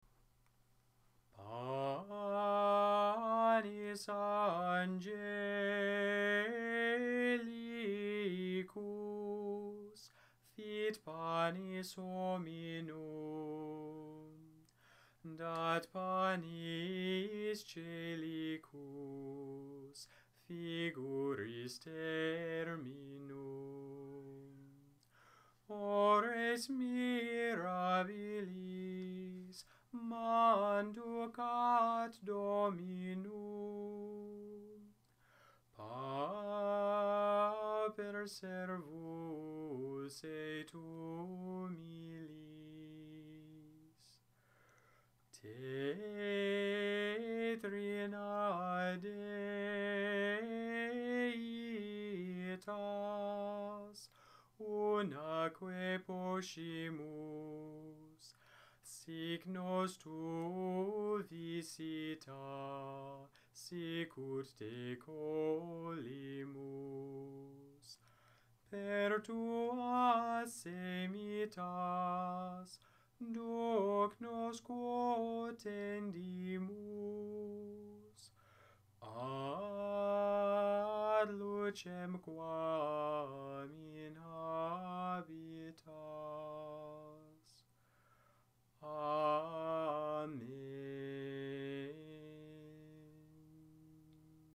Gregorian chant audios